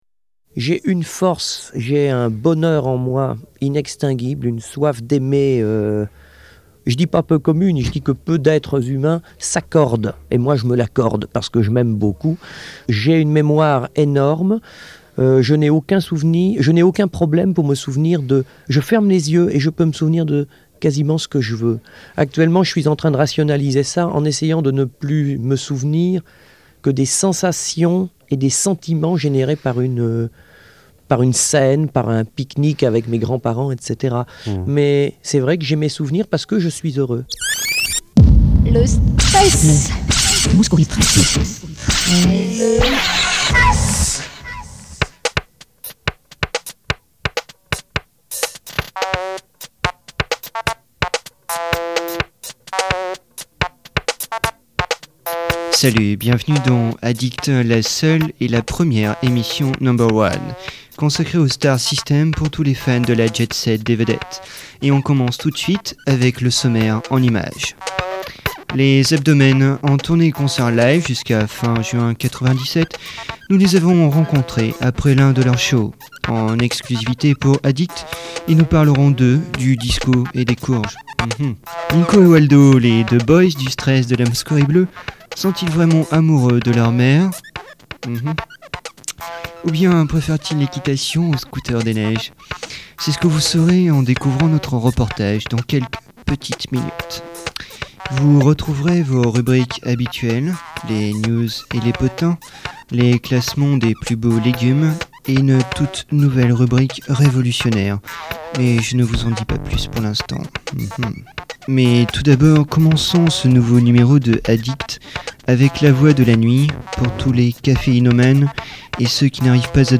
diffusion originale : radio Accords le 16.05.1997 à partir de 23h et quelques secondes.